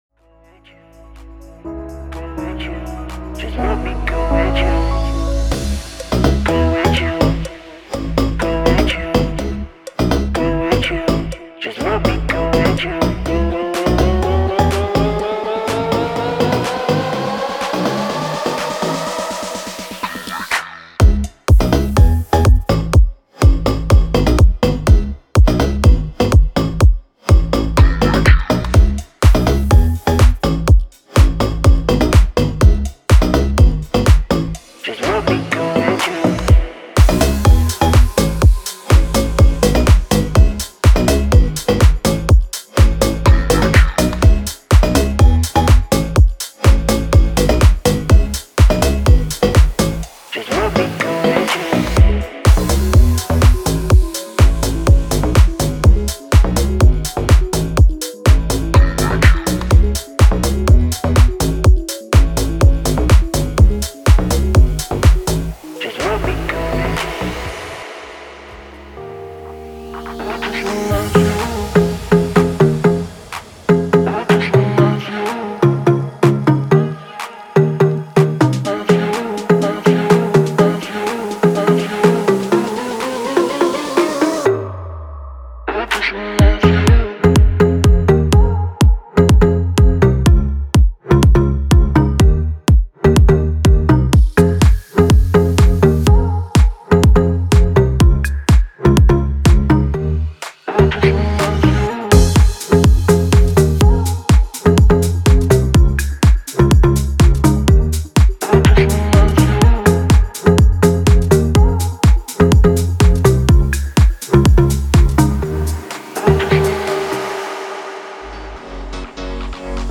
4 Vocal hooks